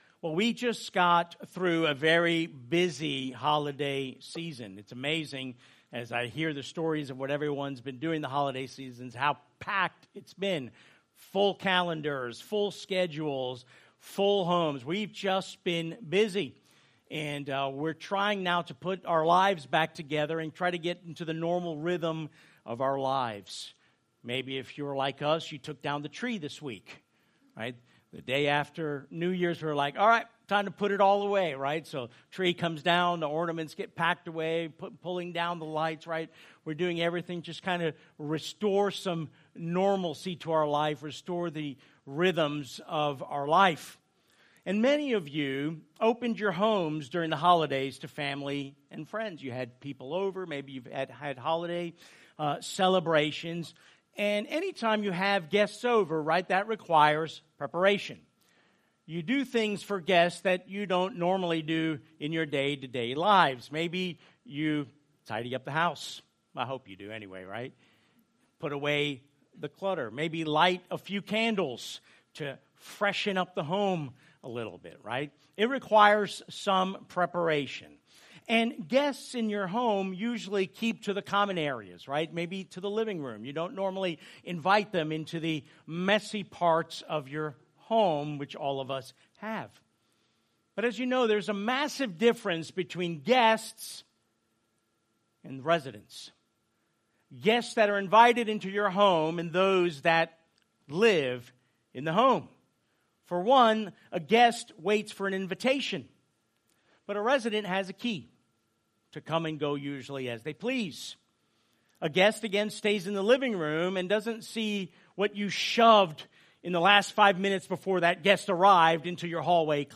Sent Church Lake Mary, Longwood, Sanford FL | Sermons